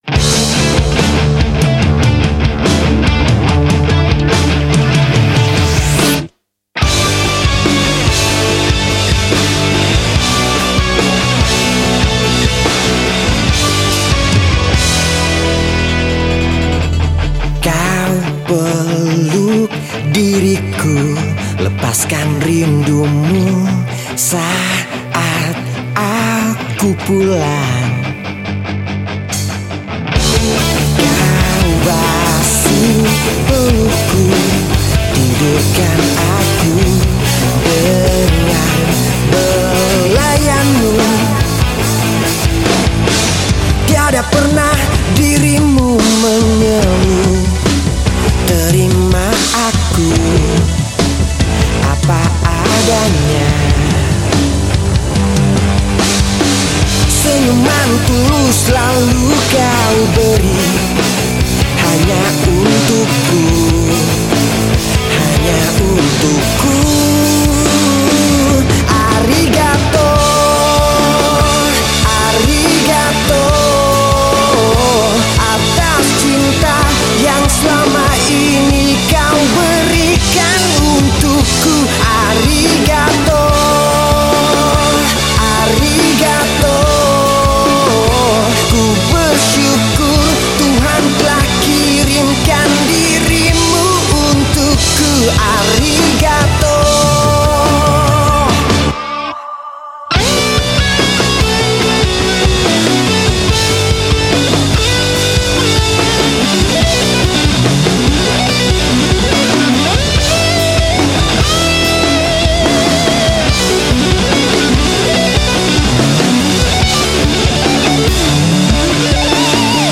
Genre Musik                          : Slow Rock Alternative